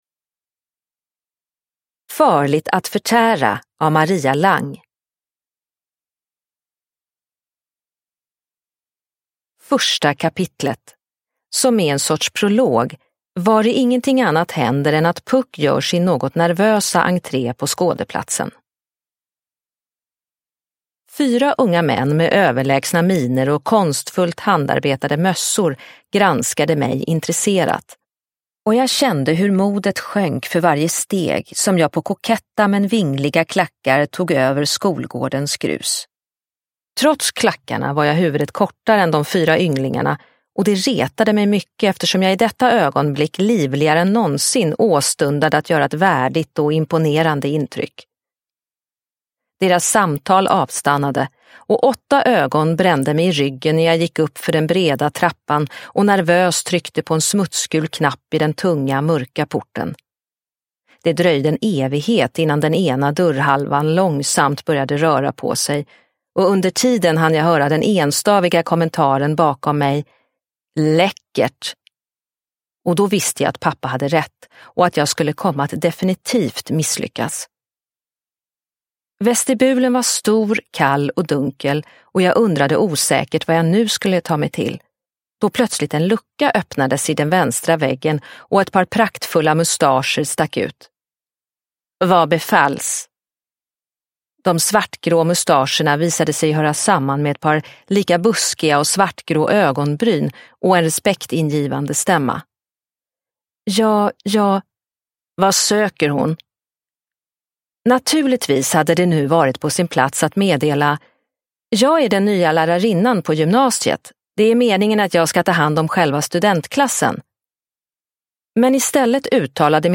Farligt att förtära – Ljudbok – Laddas ner